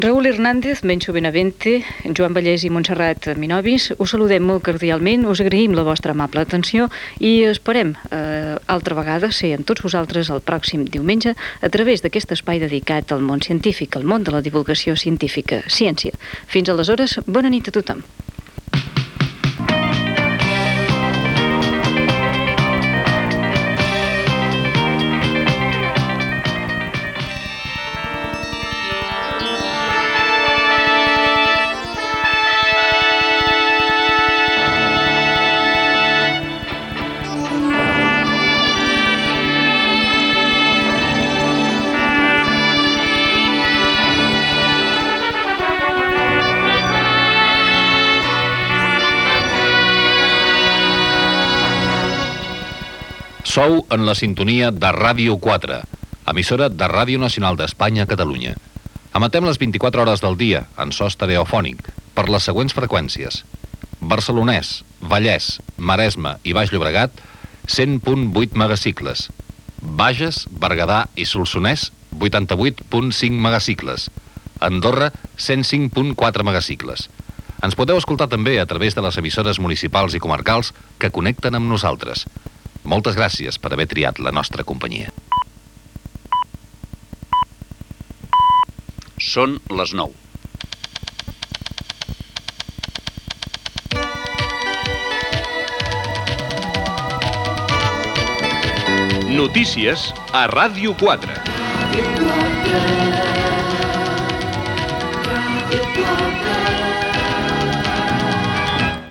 2641c3dc00af88e037c5efc038e677861cd900e8.mp3 Títol Ràdio 4 Emissora Ràdio 4 Cadena RNE Titularitat Pública estatal Nom programa Ciència Descripció Comiat del programa, sintonia, freqüències i careta del butlletí de notícies.